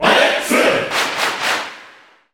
Category:Crowd cheers (SSBU) You cannot overwrite this file.
Alex_Cheer_Italian_SSBU.ogg.mp3